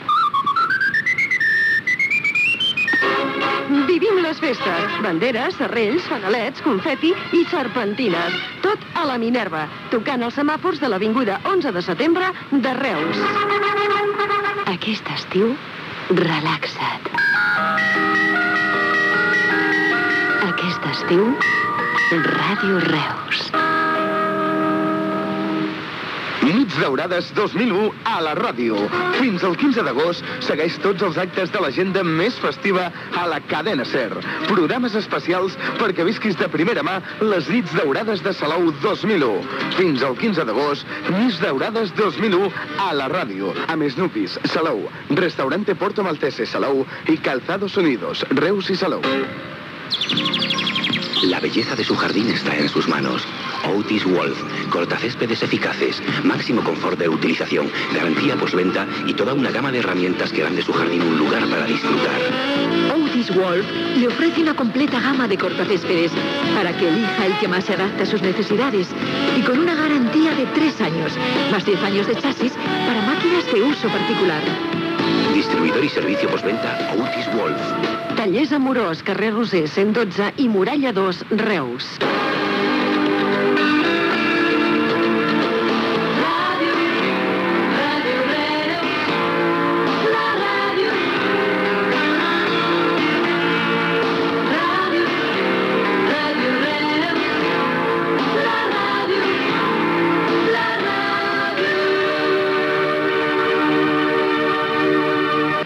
Publicitat, indicatiu de l'emissora, promoció del programa "Nits daurades de Salou 2001", publicitat, indicatiu.